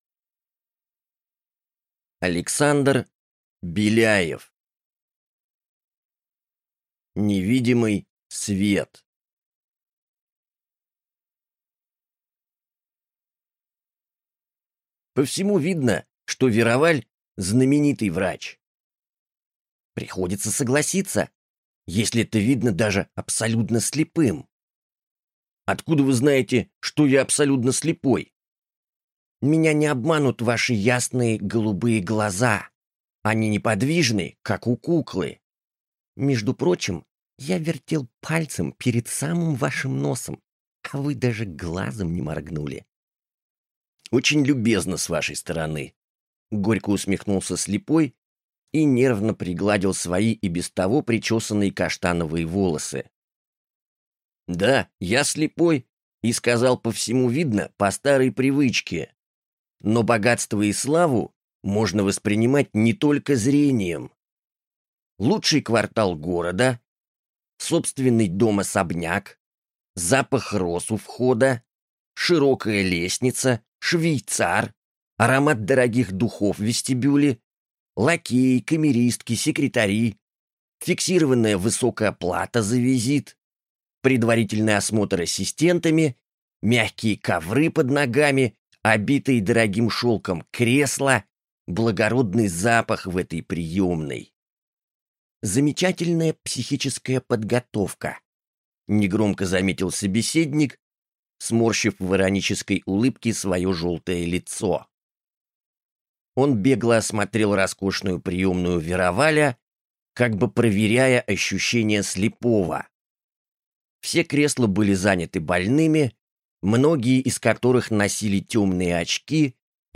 Аудиокнига Невидимый свет | Библиотека аудиокниг